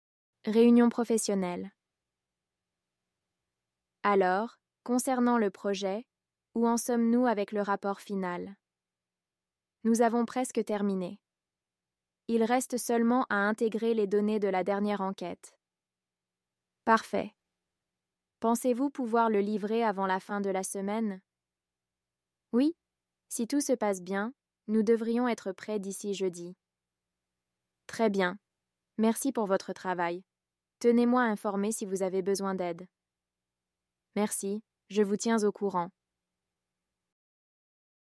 Dialogue professionnel pour une réunion